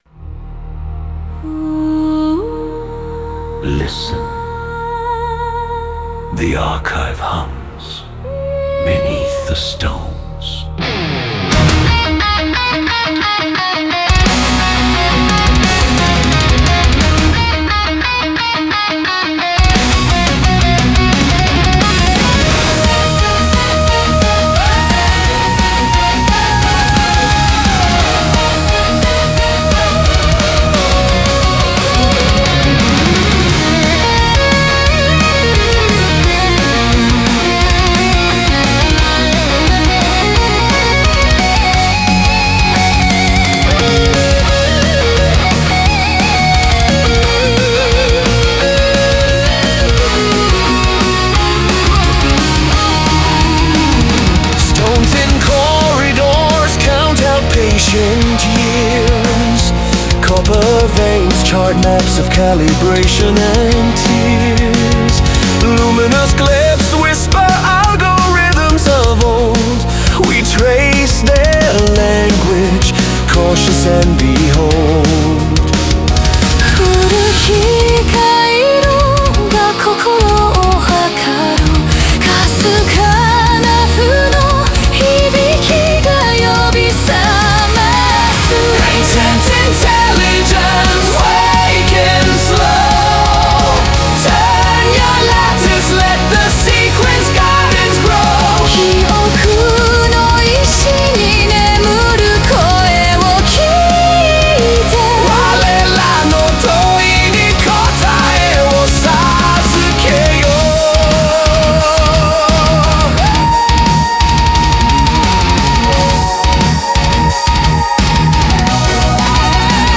Melodic Power Metal